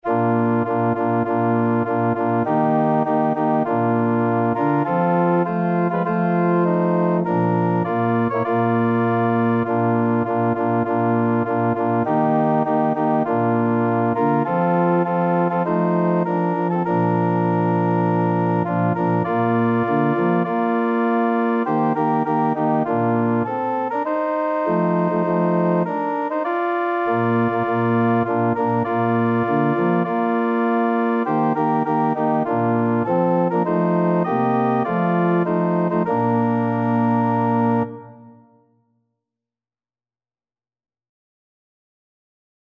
4/4 (View more 4/4 Music)
Organ  (View more Intermediate Organ Music)
Classical (View more Classical Organ Music)